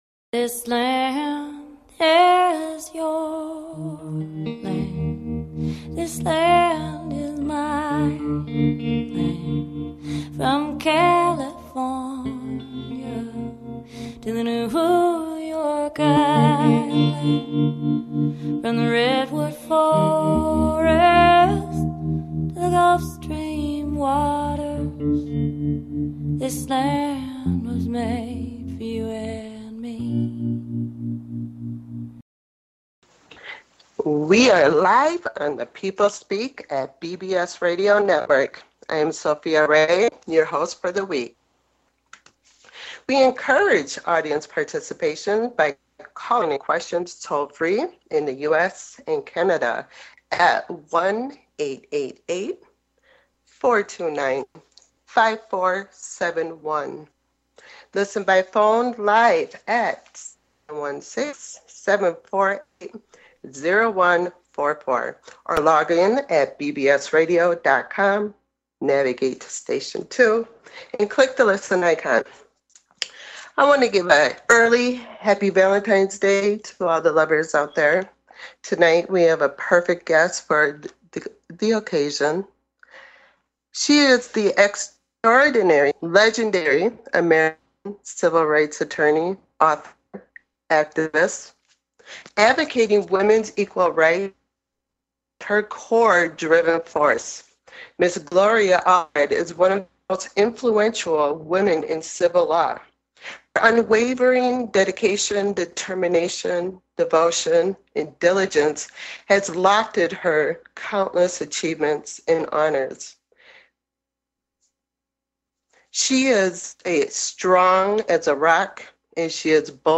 Talk Show Episode, Audio Podcast, SUPER LAWYER ON BILL COSBY and Guest Gloria Allred on , show guests , about Gloria Allred,Bill Cosby,Victims Rights,Sexual Assault Victims, categorized as Education,News
GLORIA ALLRED - Super Lawyer, speaks out about the sexual assault allegations against Bill Cosby.